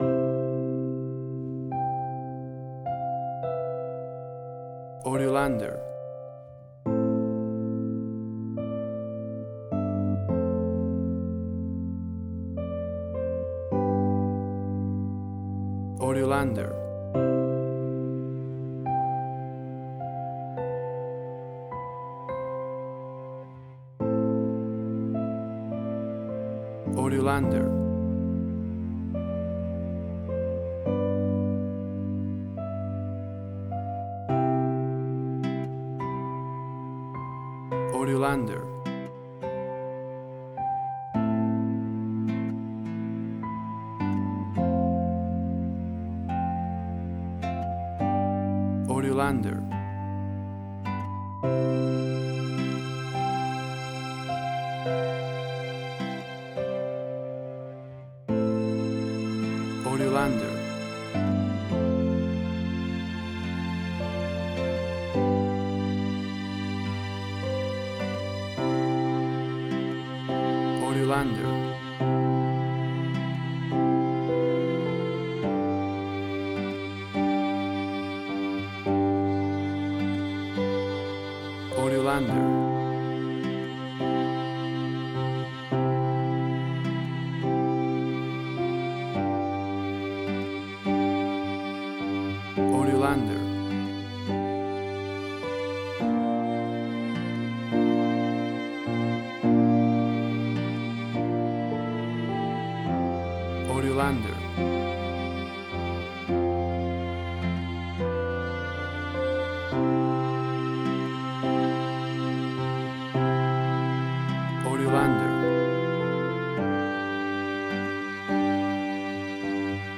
Indie Quirky.
Tempo (BPM): 105